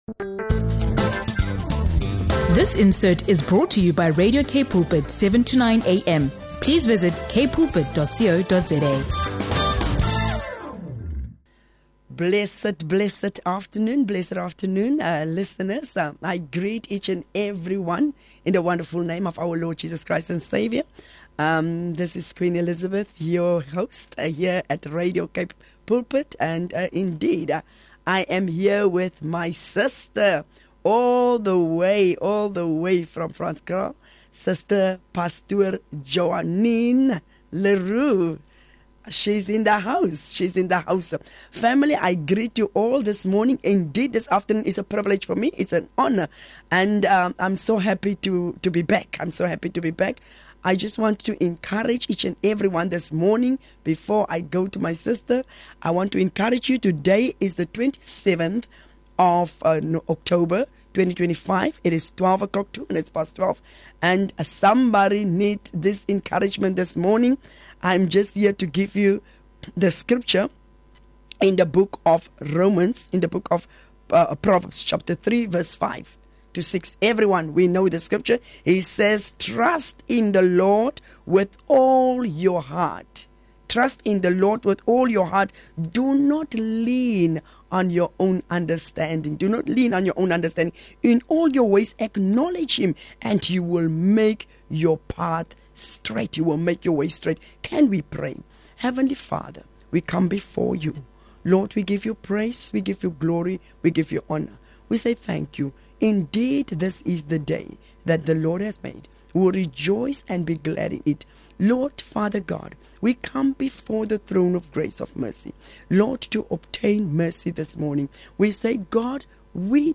Together, they dive into a moving conversation about faith, restoration, and the power of trusting God through life’s storms.